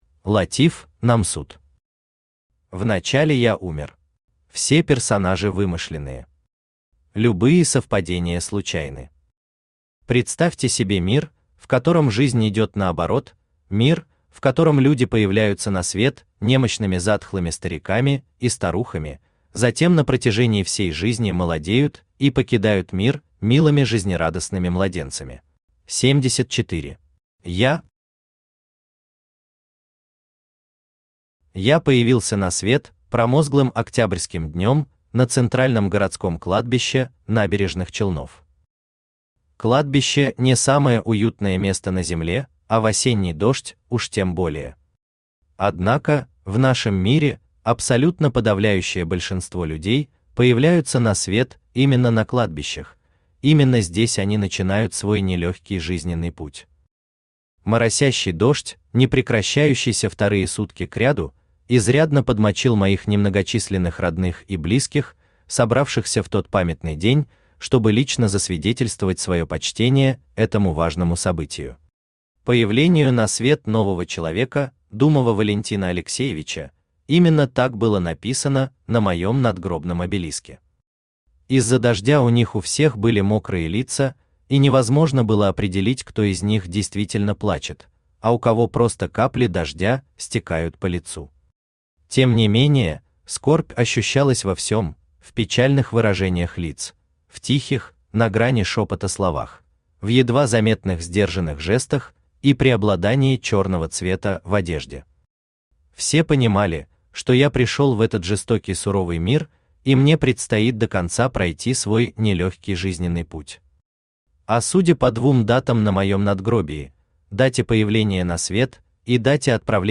Аудиокнига Вначале я умер…
Aудиокнига Вначале я умер… Автор Латив Намсуд Читает аудиокнигу Авточтец ЛитРес.